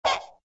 firehydrant_popup.ogg